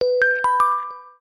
SMS Alert
Original Xiaomi Redmi 2 Notification from 2015.